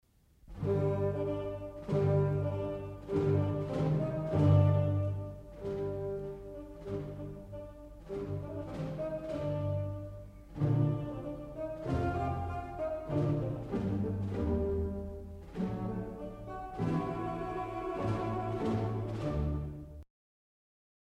Ha una voce piena e delicata ed esegue le parti gravi.
fagotto solo in orchestra
fagotto_solo.mp3